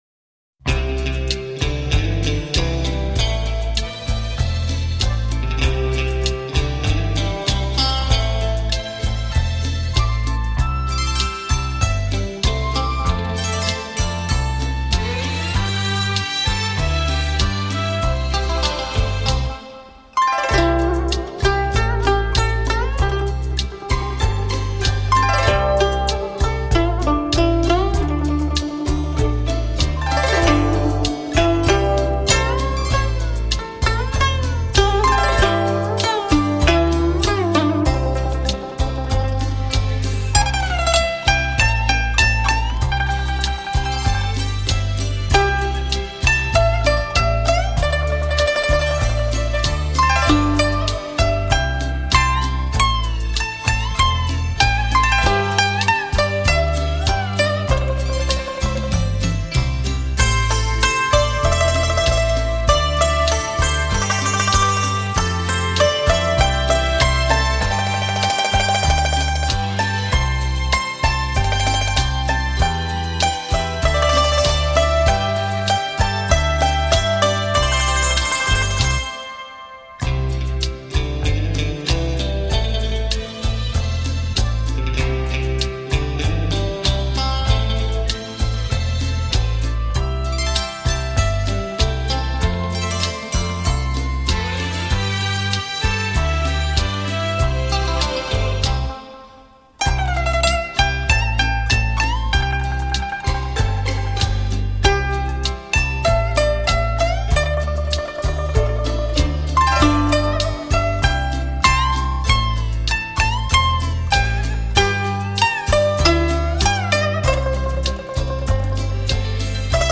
古筝演奏
古色古香的乐器，脍炙人口的名曲，袅袅琴音让人仿佛置身于山腰小亭间，仰面山上飞泻的瀑布，顿觉超凡脱俗，犹若人间仙境。